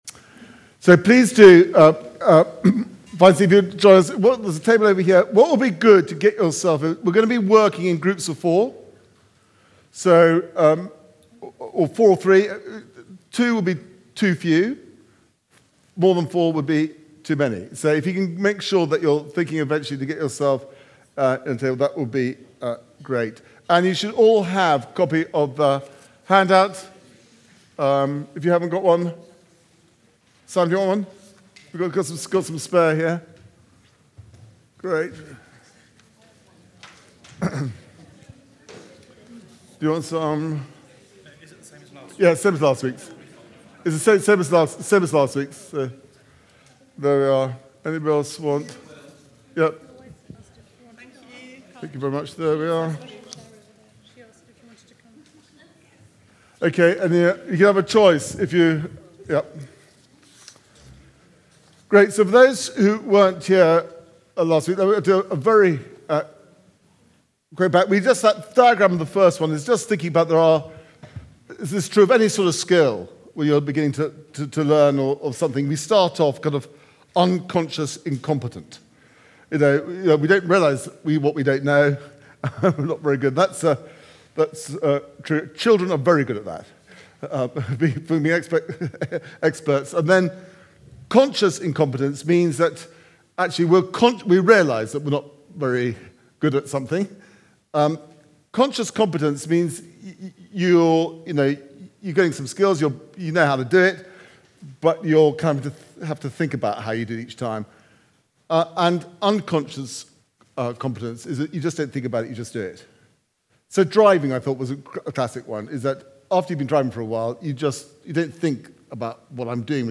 Preaching
Recorded at Woodstock Road Baptist Church on 01 March 2026.